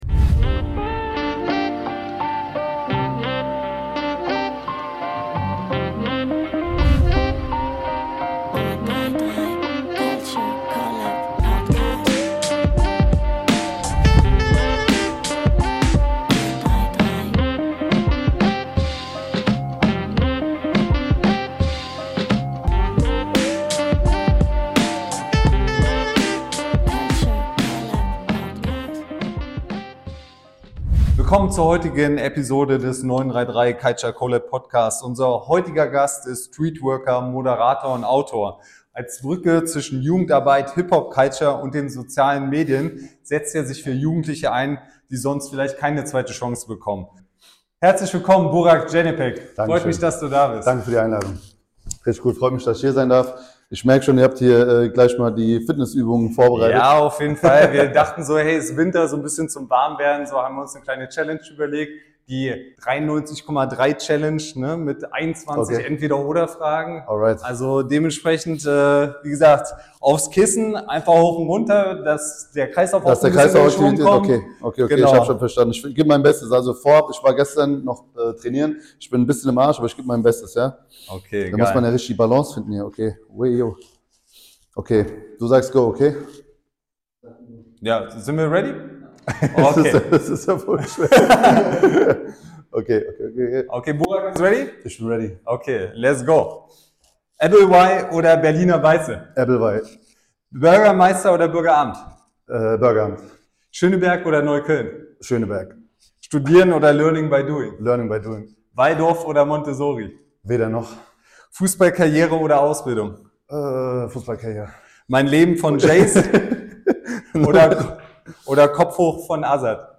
_____________________________________________________  Bei 933 CULTURE CO:LAB trifft Culture auf Mindset – echte Gespräche mit kreativen Köpfen, die Großes bewegen.